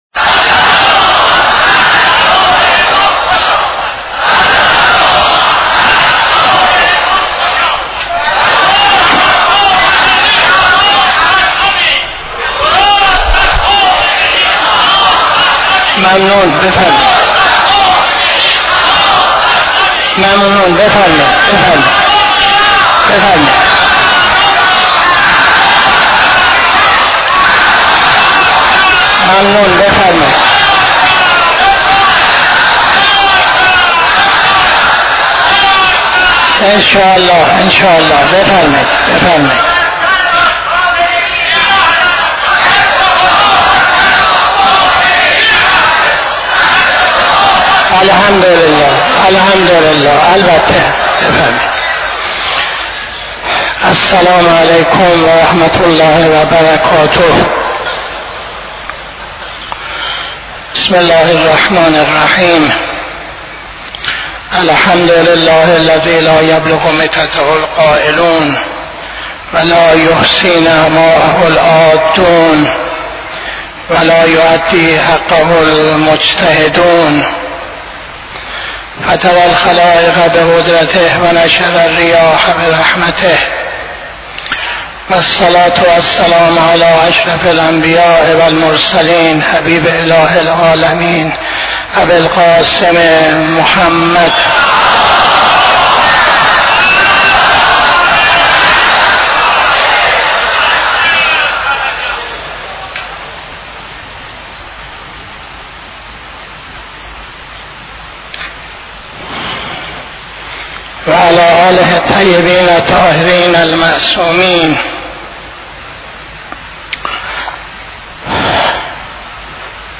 خطبه اول نماز جمعه 18-07-82